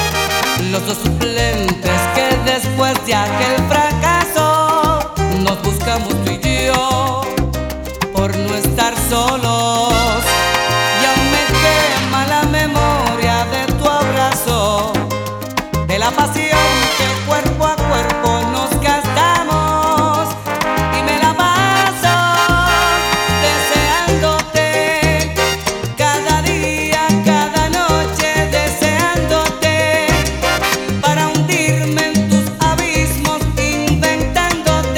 Música tropical, Latin